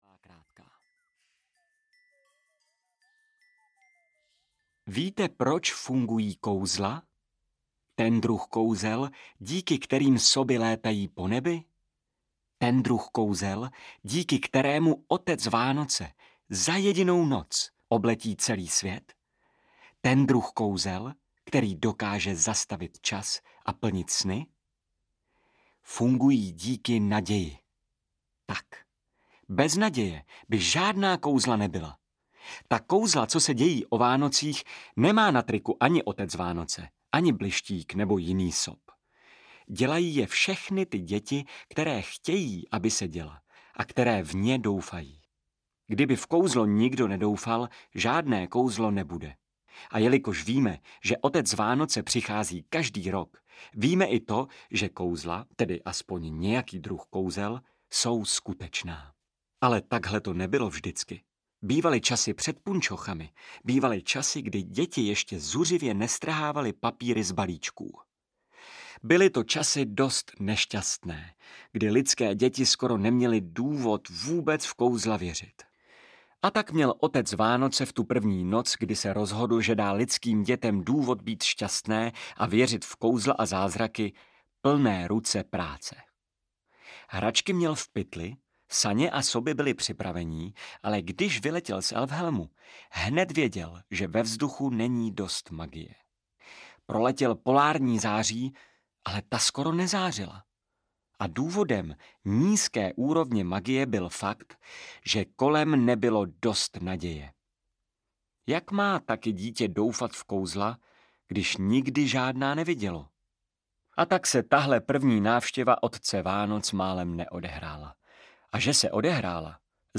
Holka, která zachránila Vánoce audiokniha
Ukázka z knihy